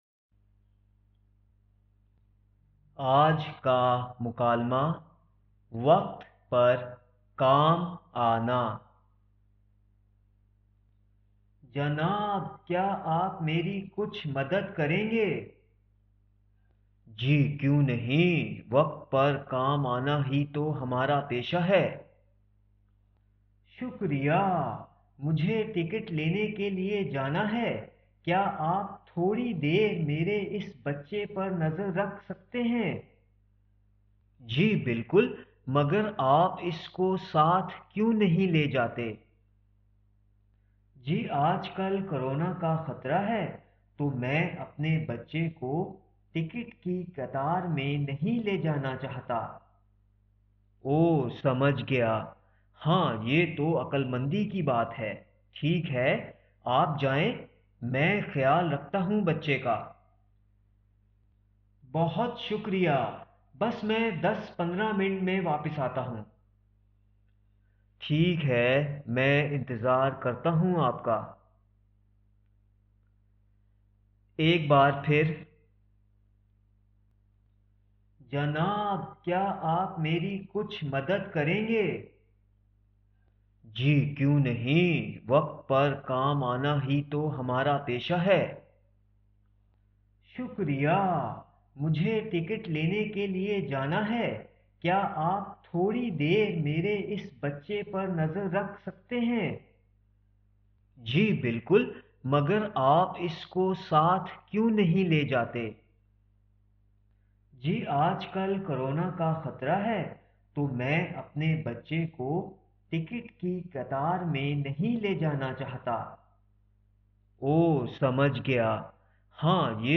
نئے اسباق>مکالمہ>وقت پر کام آنا